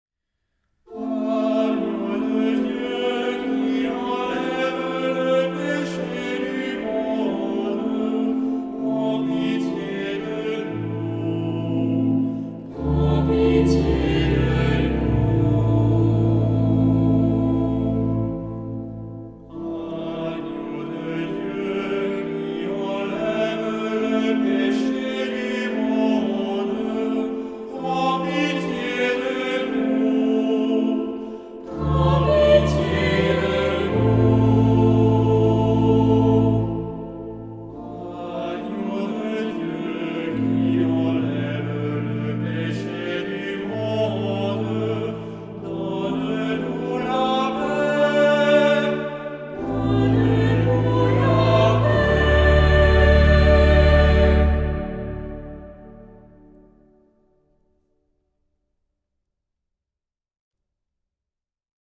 SAH (3 voices mixed).
Tonality: D tonal center